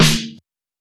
Snares
GEU_SNR (2).wav